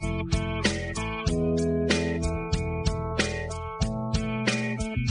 rock_and_roll.mp3